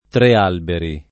[ tre # lberi ]